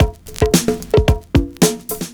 112PERCS07.wav